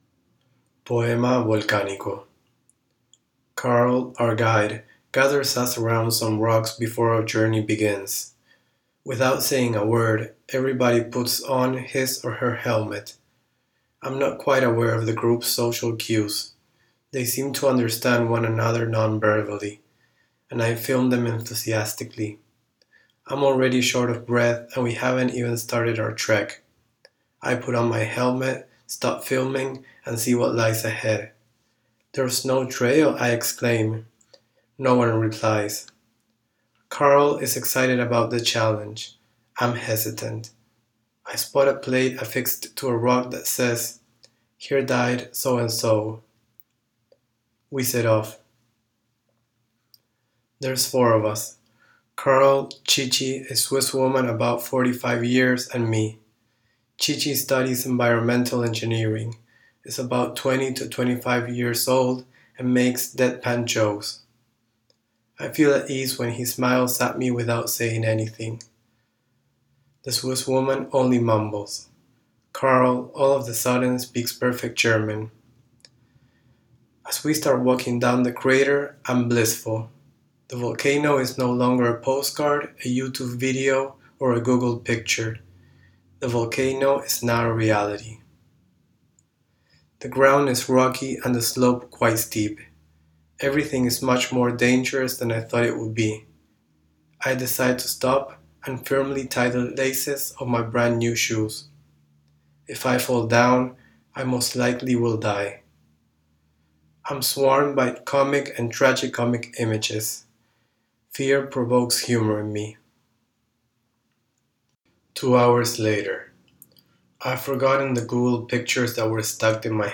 Visiting Artists Critics program at Hunter College.